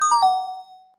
report completed.mp3